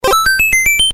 Perfect For Unblocked Sound Buttons, Sound Effects, And Creating Viral Content.